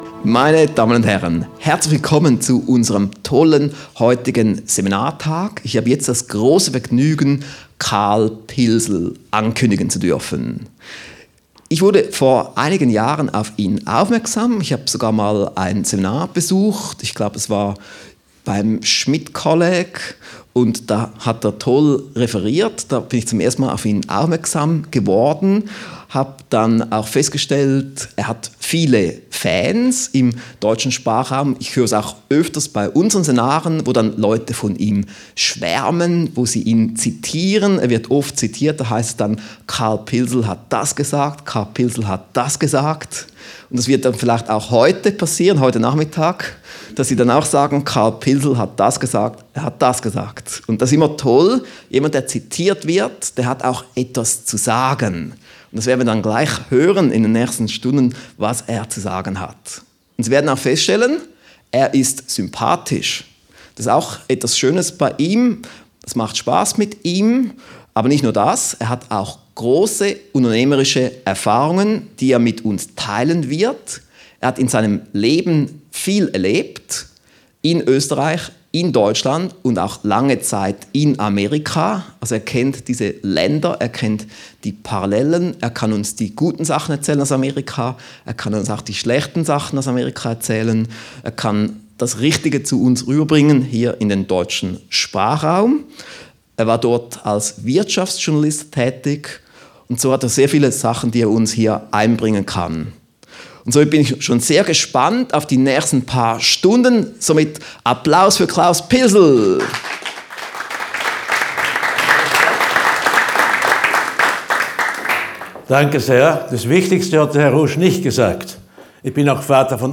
CD 1: »Das Pilsl-M-Prinzip«, LIVE-Mitschnitt Teil 01